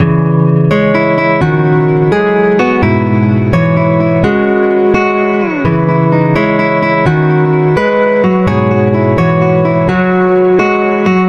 民谣木吉他一
描述：85 BPM的小调......可作为前奏或中断。
Tag: 85 bpm Folk Loops Guitar Acoustic Loops 1.90 MB wav Key : E